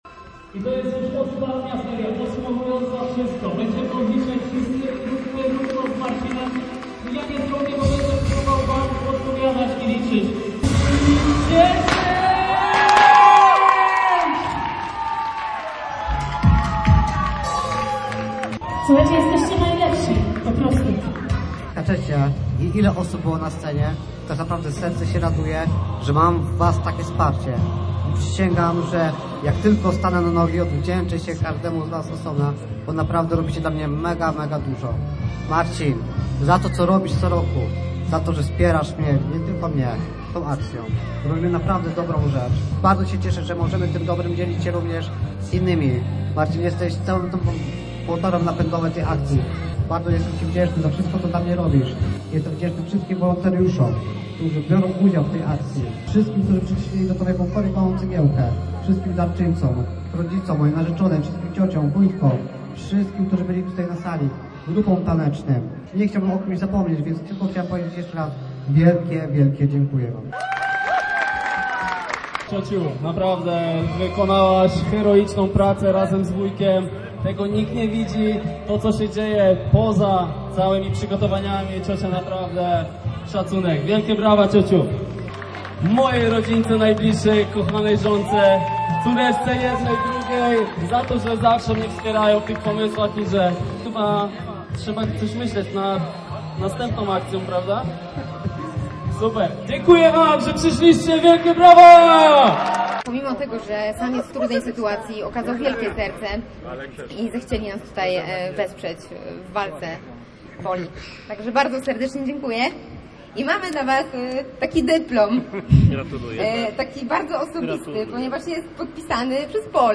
Posłuchaj relacji reportera Radia Gdańsk: https